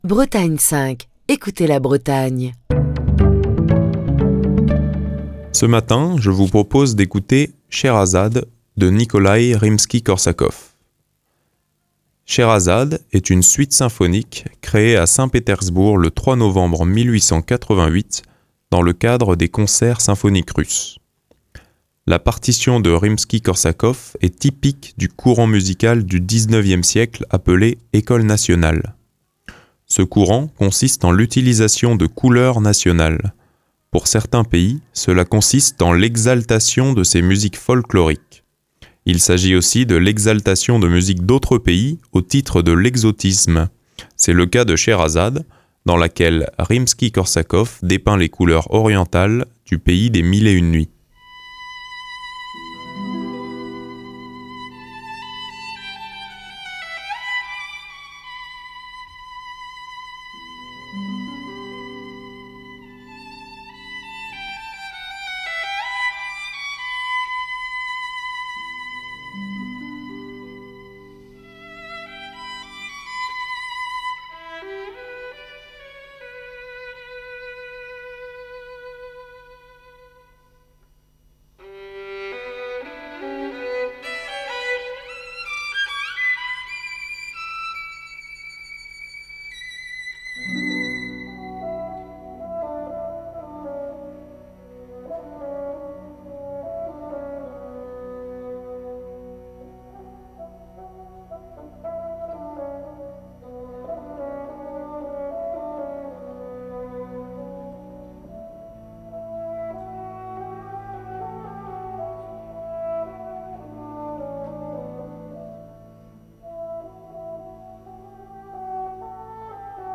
Schéhérazade est une suite symphonique de Nikolaï Rimski-Korsakov, créée à Saint-Pétersbourg le 3 novembre 1888 dans le cadre des Concerts symphoniques russes.
Nous écoutons un extrait de Shéhérazade de Nikolai Rimsky-Korsakov, joué par l’orchestre philharmonique du théâtre de Turin, sous la direction de Gianandrea Noseda en 2018.